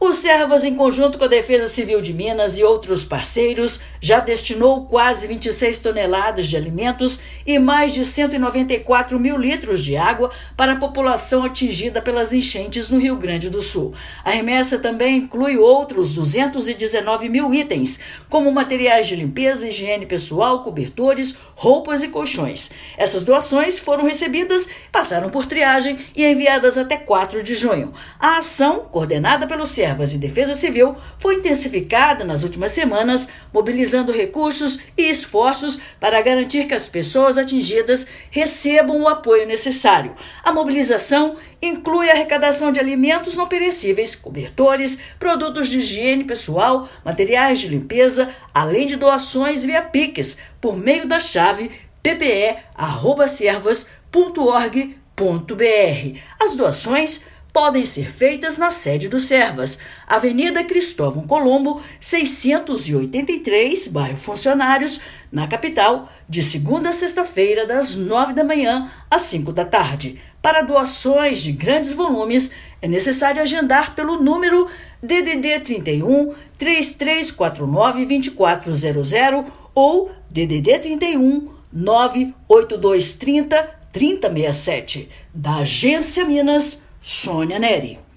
Servas e Defesa Civil intensificam as operações para mobilizar recursos e esforços para apoiar a população atingida pelas enchentes. Ouça matéria de rádio.